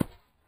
default_place_node_hard.1.ogg